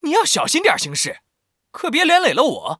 文件 文件历史 文件用途 全域文件用途 Timothy_fw_03.ogg （Ogg Vorbis声音文件，长度2.8秒，102 kbps，文件大小：35 KB） 文件说明 源地址:游戏解包语音 文件历史 点击某个日期/时间查看对应时刻的文件。 日期/时间 缩略图 大小 用户 备注 当前 2019年1月24日 (四) 04:35 2.8秒 （35 KB） 地下城与勇士  （ 留言 | 贡献 ） 分类:蒂莫西(地下城与勇士) 分类:地下城与勇士 源地址:游戏解包语音 您不可以覆盖此文件。